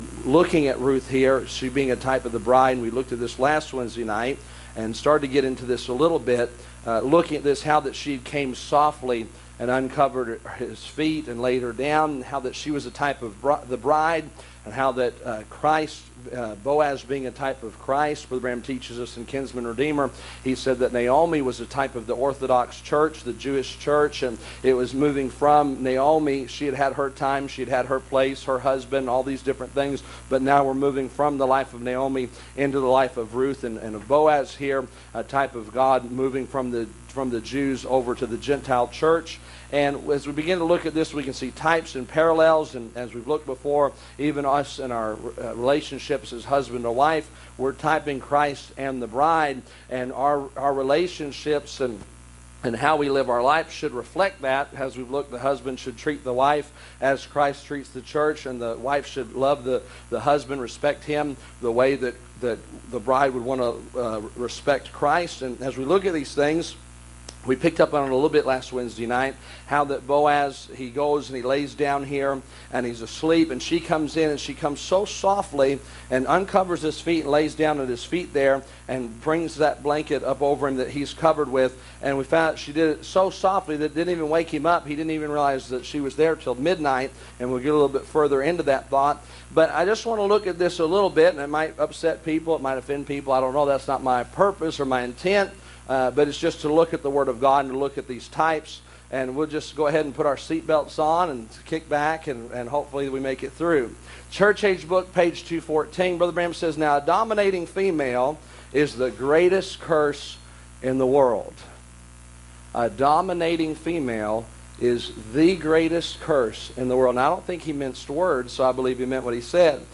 Passage: Ruth 4:5 Service Type: Wednesday Evening